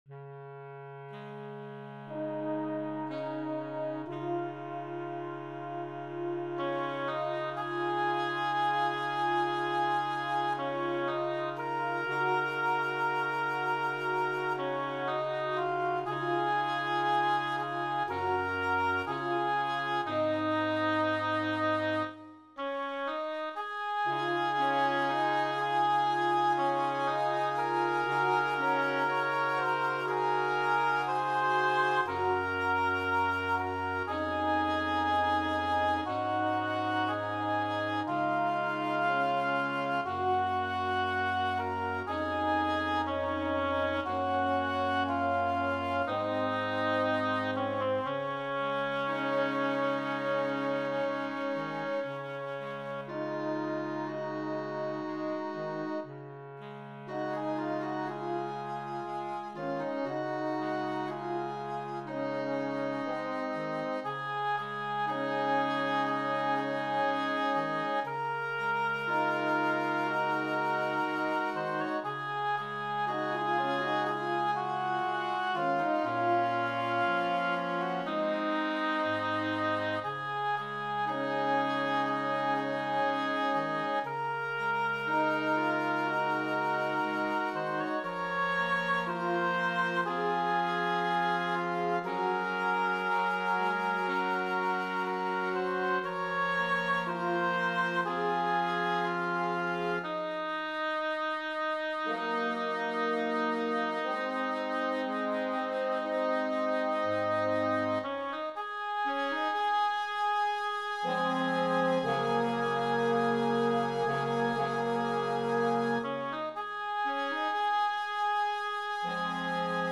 Voicing: 5 Woodwinds